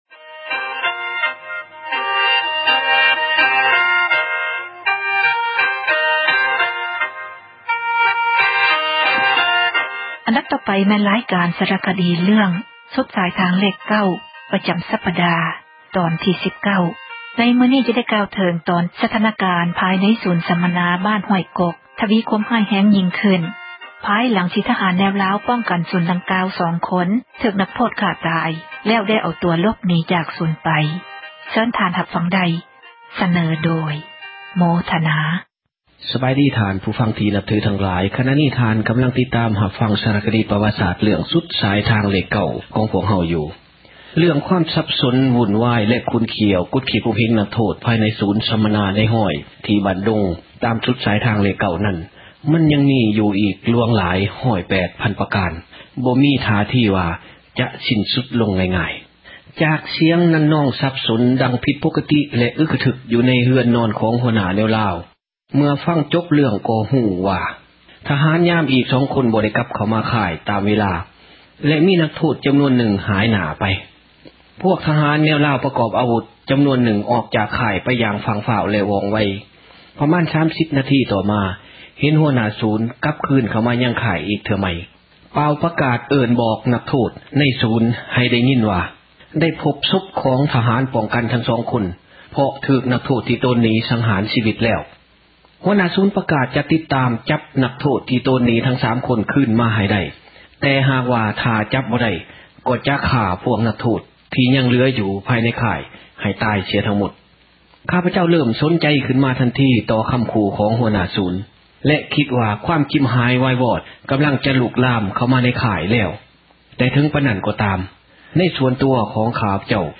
ຣາຍການສາຣະຄະດີ ເຣື້ອງ ”ສຸດສາຍທາງເລຂ 9” ປະຈຳສັປດາ ຕອນທີ 19 ໃນມື້ນີ້ຈະໄດ້ກ່າວເຖິງ ຕອນທີ່ສະຖານະການ ພາຍໃນສູນສັມມະນາ ບ້ານຫ້ວຍກົກ ທະວີຄວາມຮ້າຍແຮງ ຍິ່ງຂື້ນ ພາຍຫລັງທີ່ທະຫານແນວລາວ ປ້ອງກັນສູນດັ່ງກ່າວ ສອງຄົນຖືກນັກໂທດຂ້າຕາຍ ແລ້ວໄດ້ເອົາຕົວ ຫລົບໜີຈາກສູນໄປ.